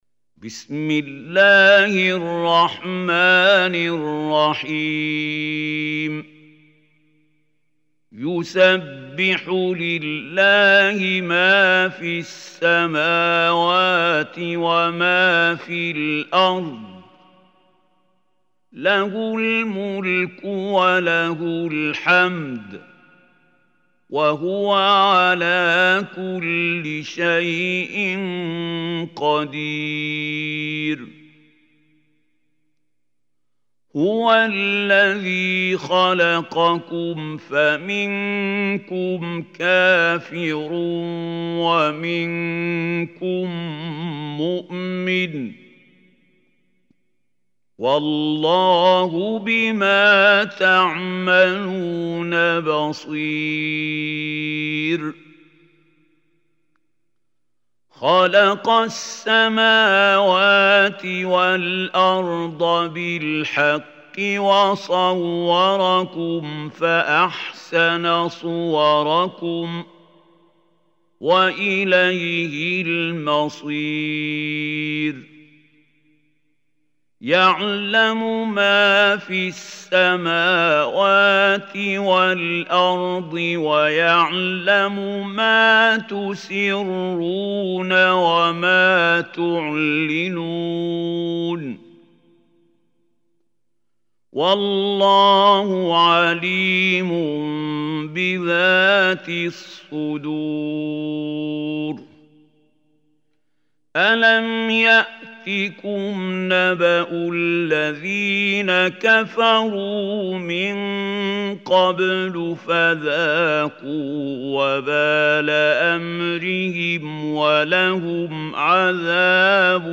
Surah Taghabun Recitation by Khalil Hussary
Surah Taghabun is 64 surah of Holy Quran. Listen or play online mp3 tilawat / recitation in Arabic in the beautiful voice of Sheikh Mahmoud Khalil Hussary.